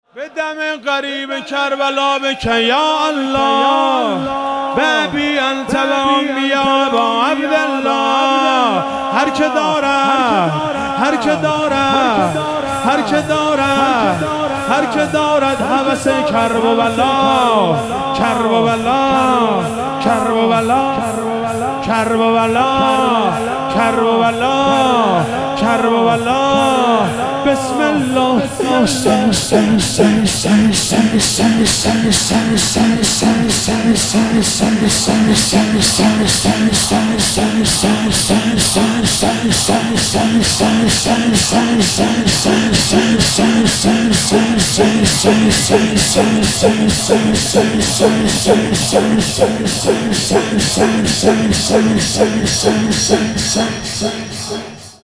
شب چهارم محرم 97 - شور - به دم غریب کربلا